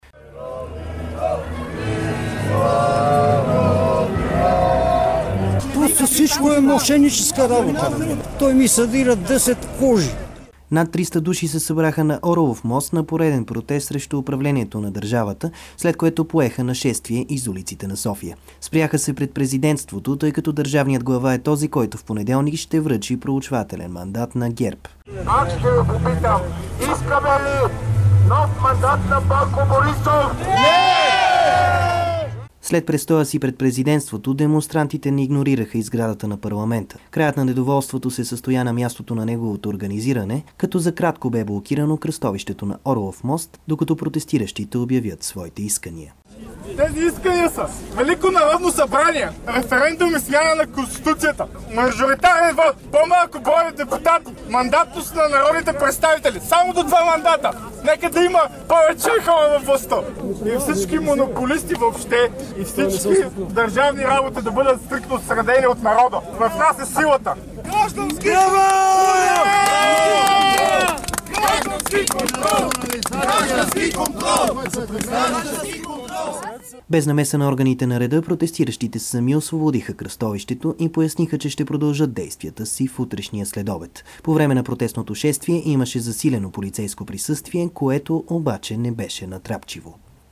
Обобщен репортаж от протеста в София